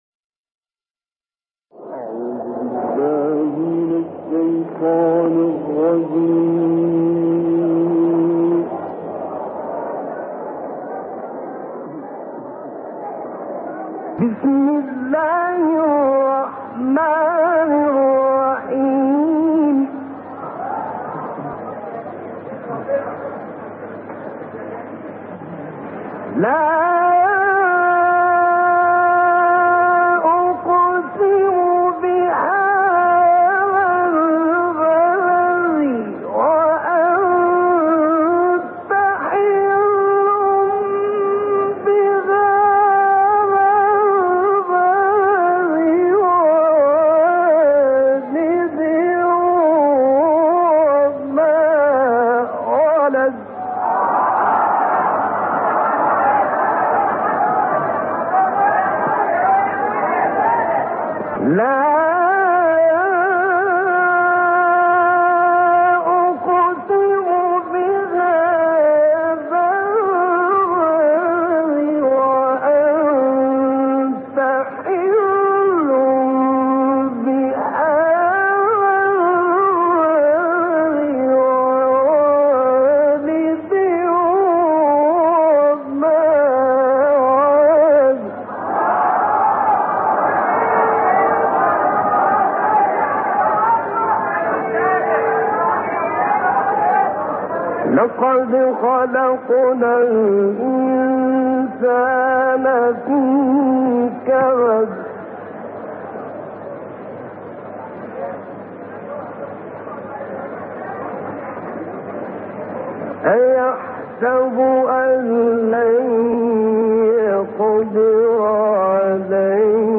تلاوت کوتاه مجلسی راغب مصطفی غلوش از آیه 1 تا 18 سوره بلد و 1 تا 5 سوره علق به مدت 6 دقیقه و 15 ثانیه